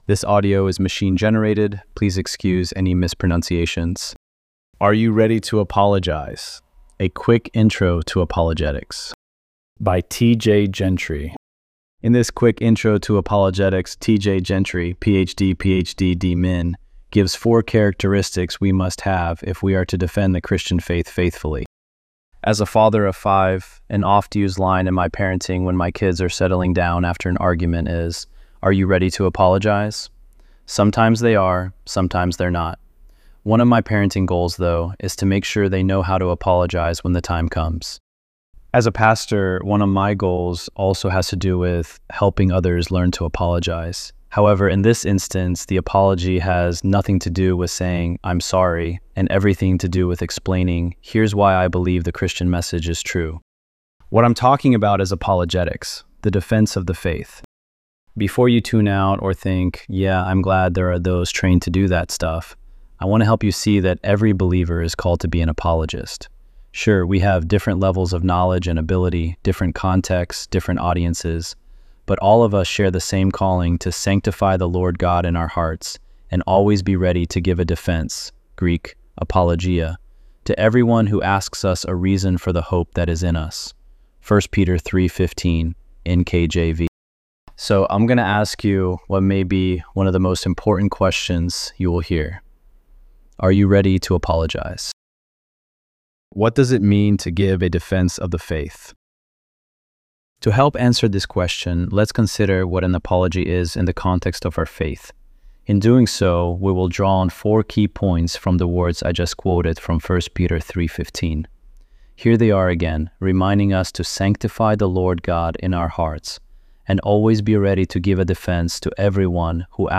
ElevenLabs_3_7.mp3